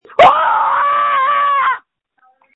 Screams from November 29, 2020
• When you call, we record you making sounds. Hopefully screaming.